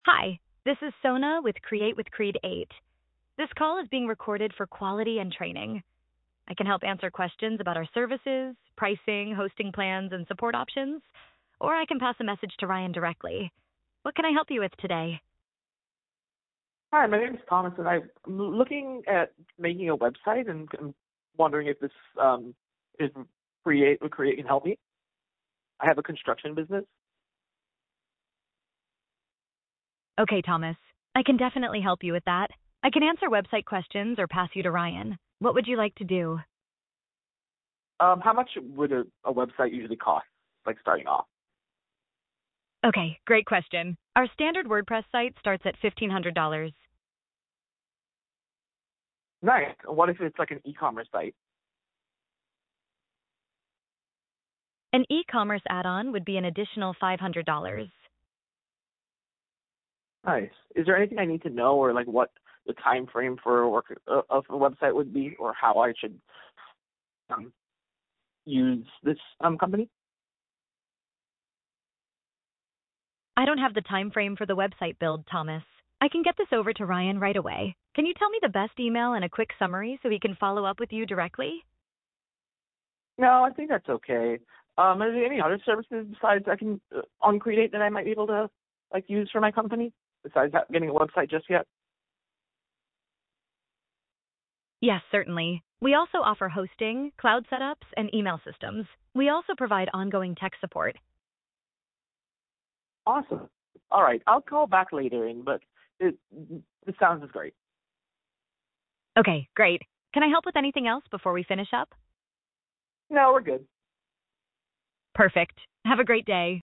Sona — The AI Voice Agent
Example Call
When someone calls Creed8 now, a natural-sounding voice answers.